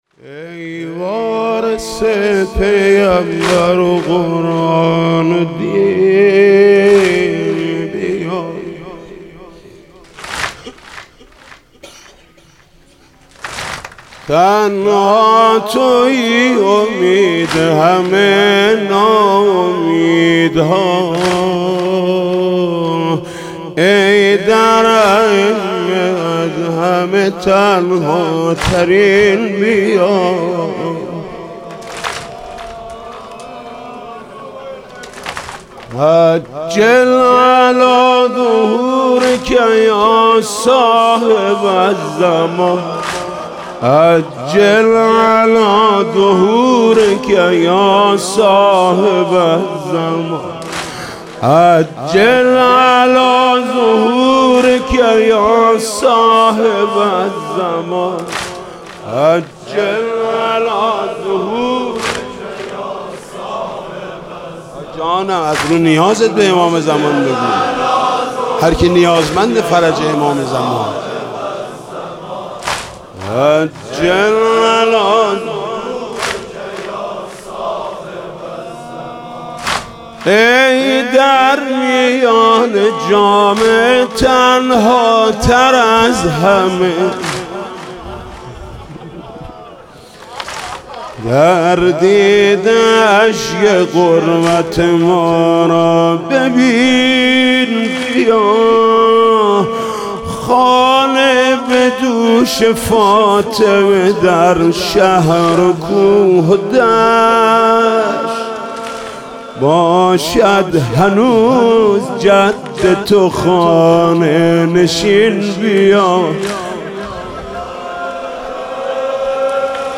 واحد: ای وارث پیمبر و قرآن و دین، بیا